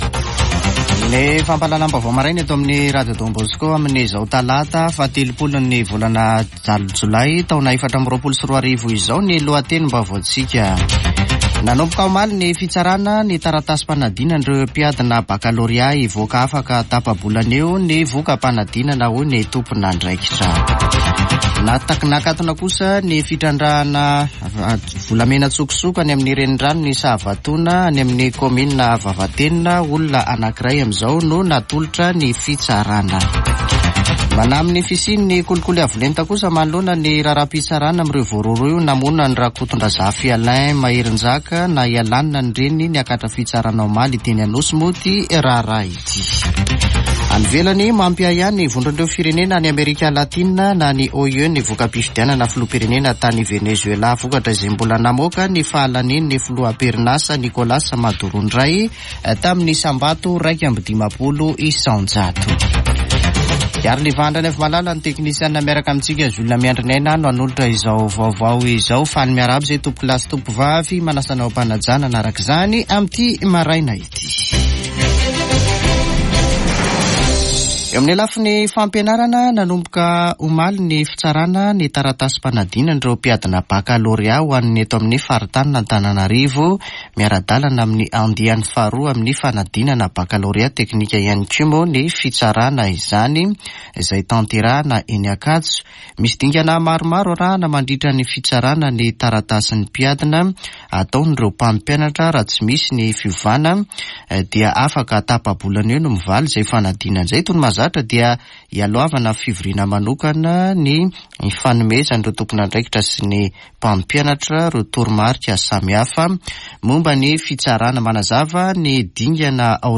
[Vaovao maraina] Talata 30 jolay 2024